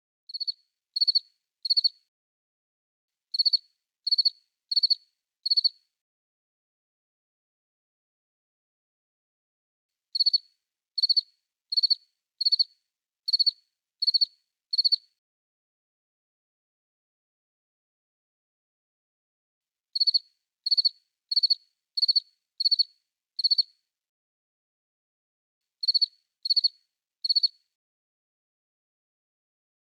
Enhver, der ønsker at forkæle sig selv med sommerlige lyde 365 dage om året, vil elske denne lyd!
Den afslappende kvidren af fårekyllinger bringer solskin ind i ethvert rum.
Satellitebox_Cricket_soundfile_30s.mp3